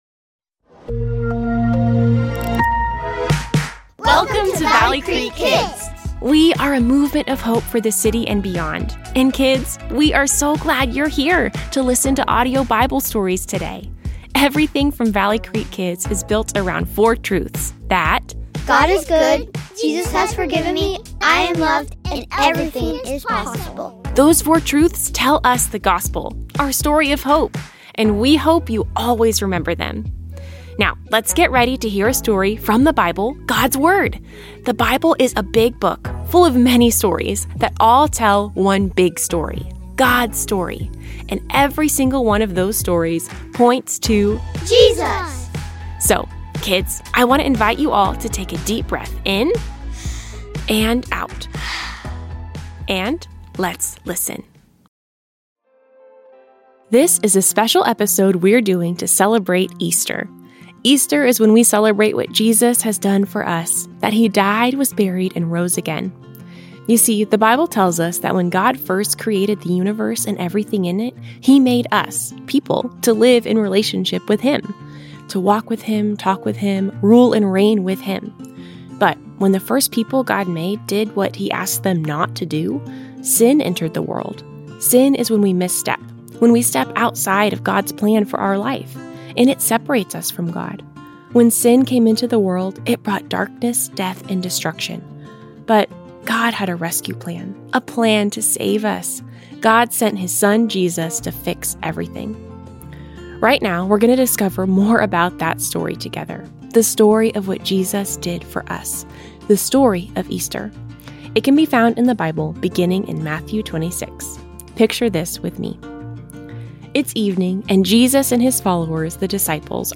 The Easter Story Kids Audio Bible Stories Share March 30, 2026 Add to My List We're releasing a special episode to celebrate Easter! At Easter, we celebrate what Jesus has done for us - that He died, was buried, and rose again to offer us forgiveness and make a way for us to live in relationship with God forever.
Easter Audio Bible Story - V1.mp3